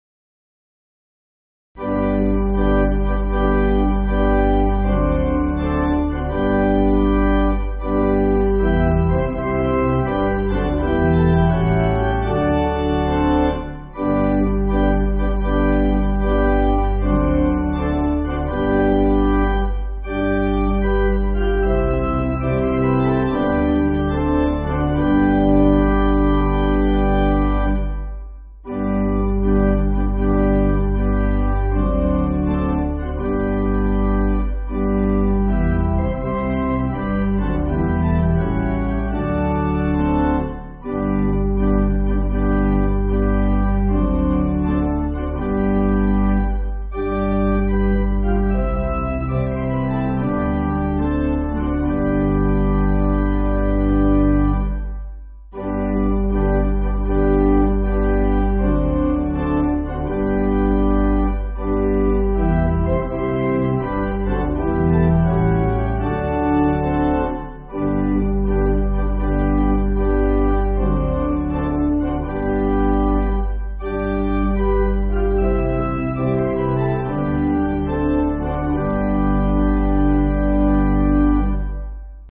Organ
(CM)   5/G